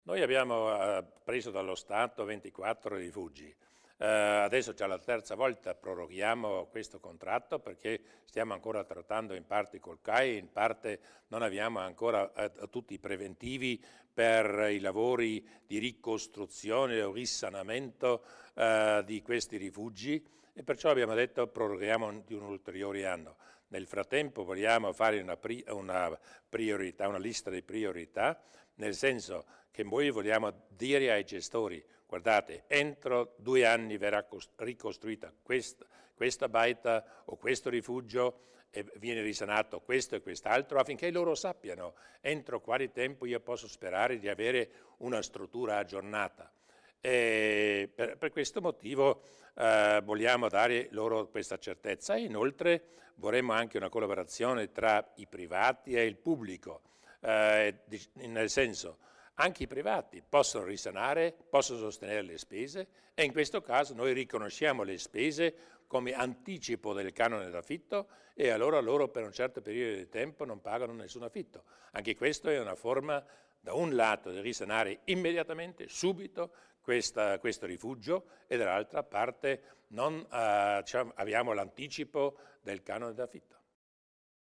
Il Presidente Durnwalder illustra i prossimi passi per la gestione dei rifugi